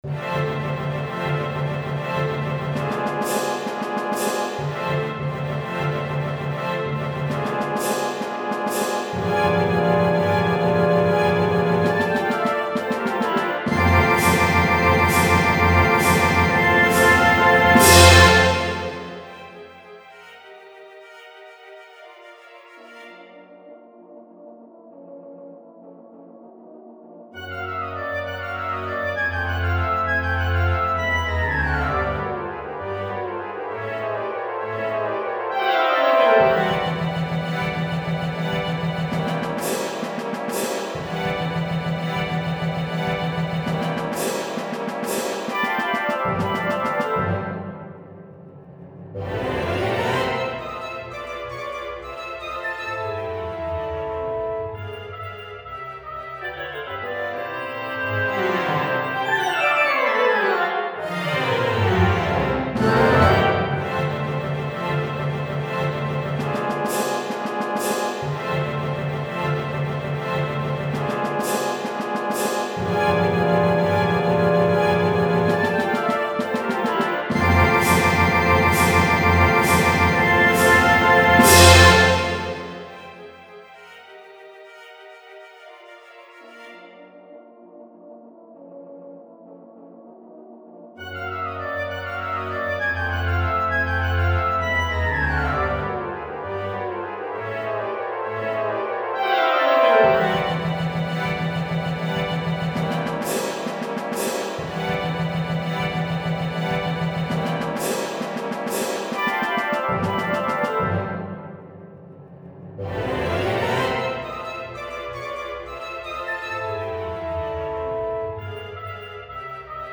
Fünf Pentagramme (großes Orchester), Opus 40 (1987) -